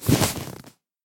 1.21.5 / assets / minecraft / sounds / mob / horse / leather.ogg
leather.ogg